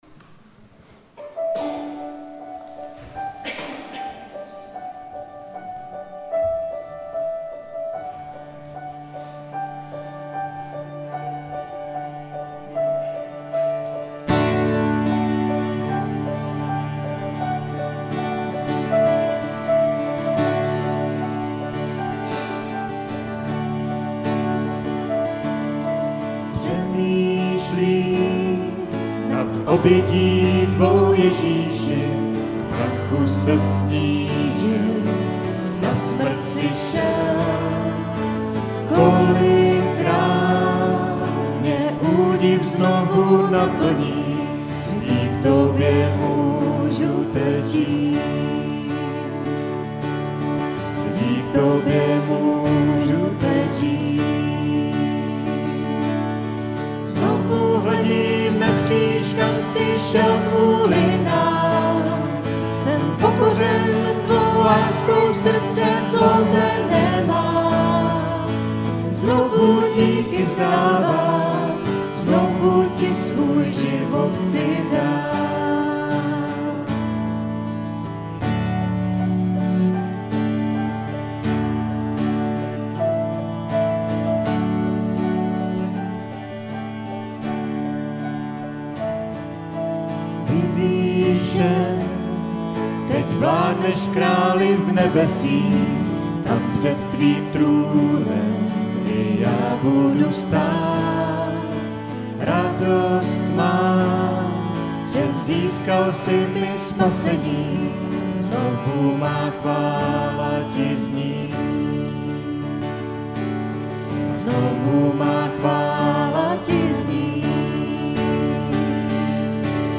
Chvály: 4.9.2016 – Znovu; Pojď, teď je ten čas; Haleluja, sláva; Nikdy nekončící milost
Událost: Bohoslužby Autor: Skupina CB Praha 2